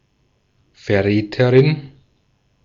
Ääntäminen
Ääntäminen Haettu sana löytyi näillä lähdekielillä: saksa Käännös Ääninäyte 1. traîtresse {f} 2. traître {m} France (Paris) Artikkeli: die .